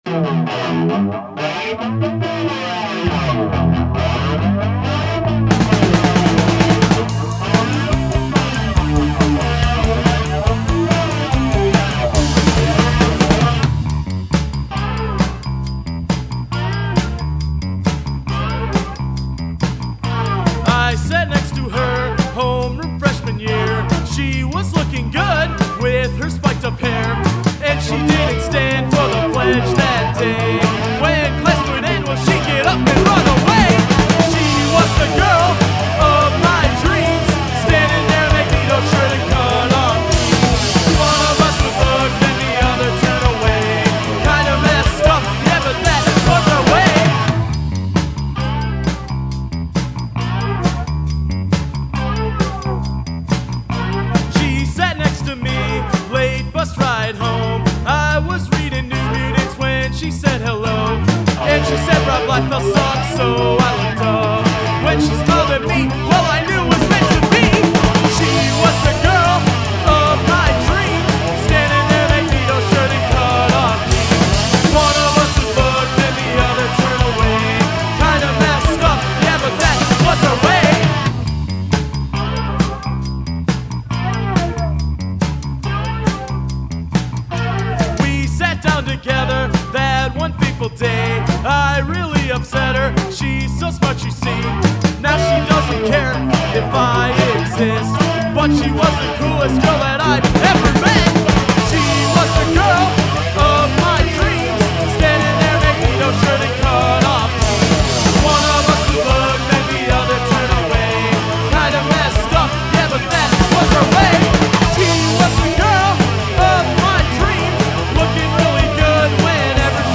Music Style: Punk